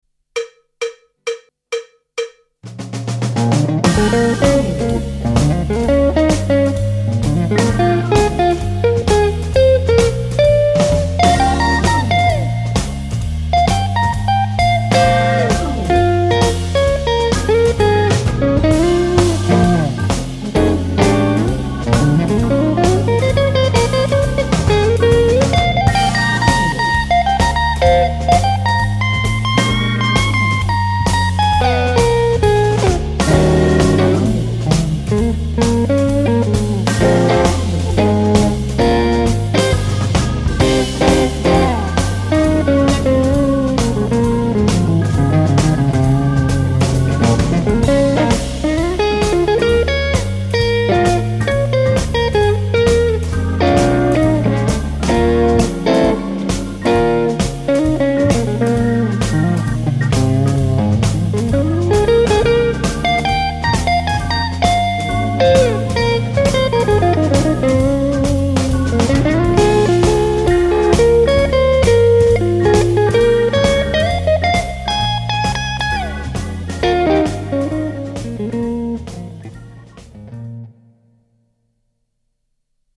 Tarjolla olisi nyt mukavan letkeää groovea.
- kun osallistut, soita soolo annetun taustan päälle ja pistä linkki tähän threadiin.